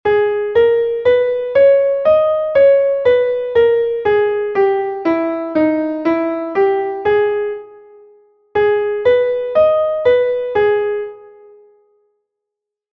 2º Despois buscamos a tonalidade, e cantamos a escala e o arpexio.
escala_arpegio_sol_num__menor.mp3